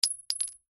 Звук упавшего на пол патрона